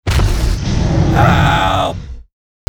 manscare.wav